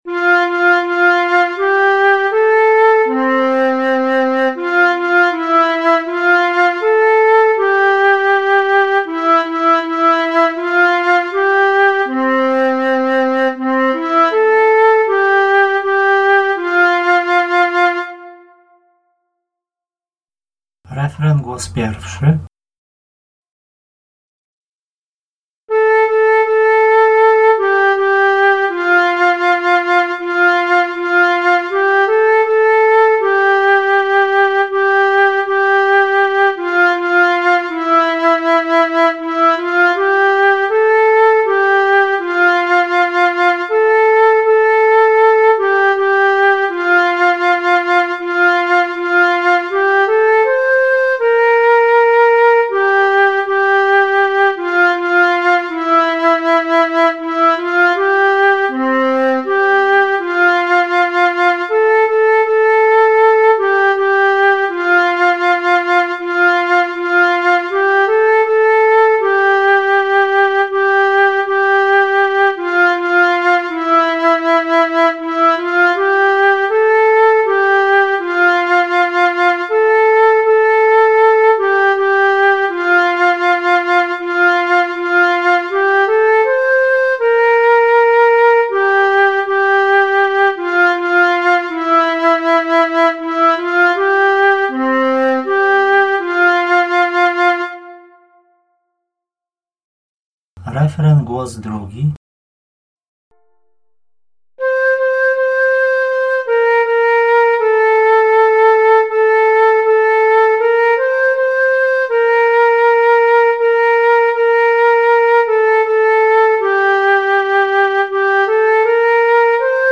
jan_z_dukli_o,_swiety_janie_2_gl_do_nauki.mp3